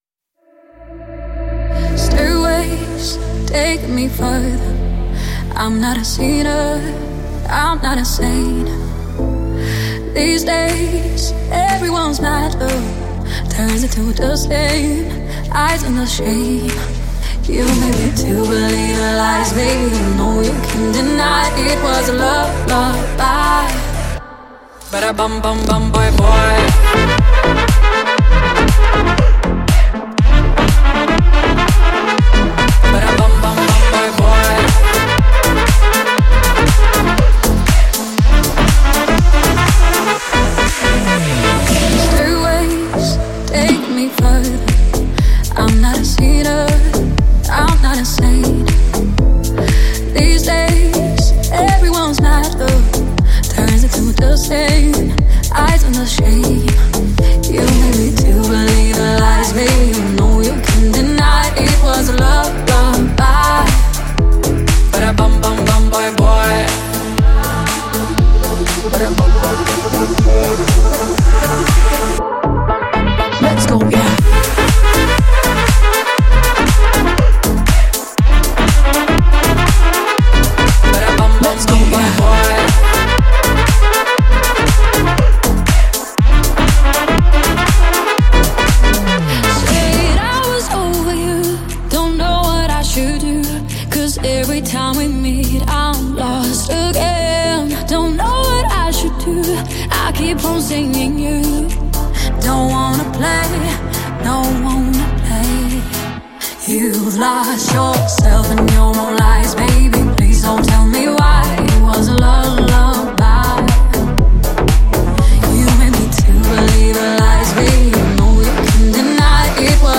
دانلود آهنگ پاپ